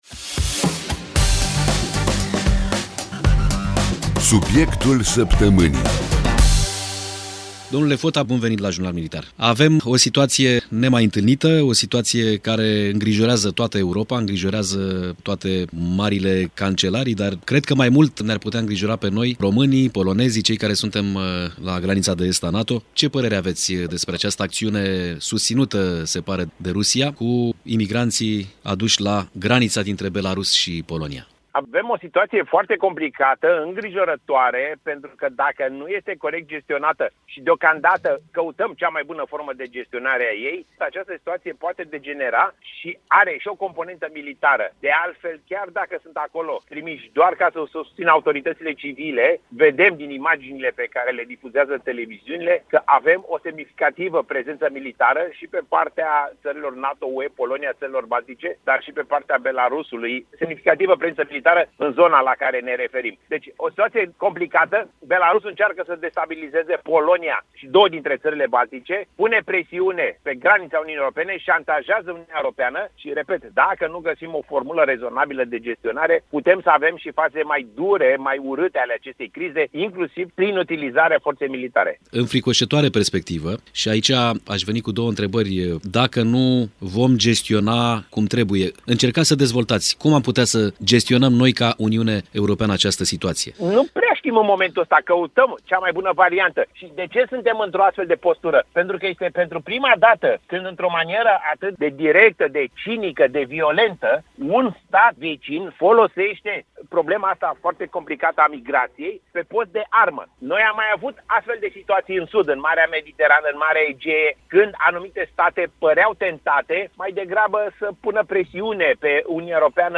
Este un interviu pe această temă a crizei actuale de la granița dintre Polonia și Belarus, în câteva secunde ascultăm.